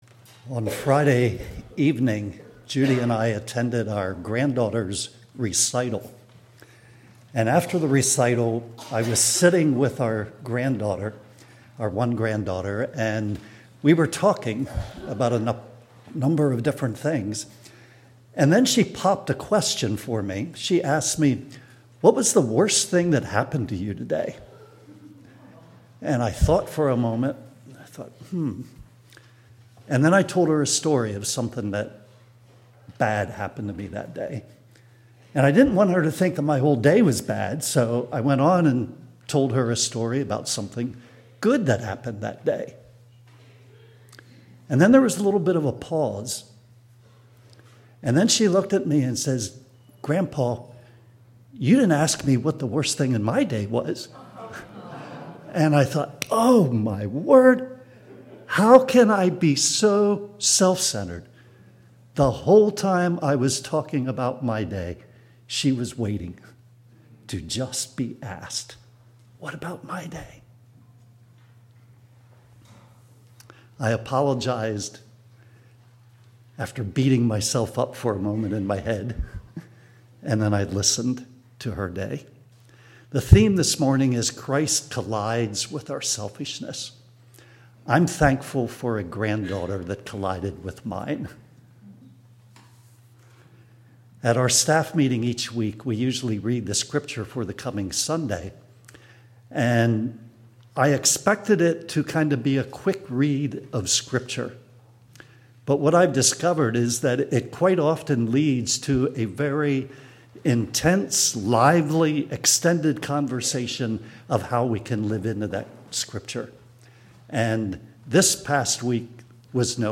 3/30/2025 Sermon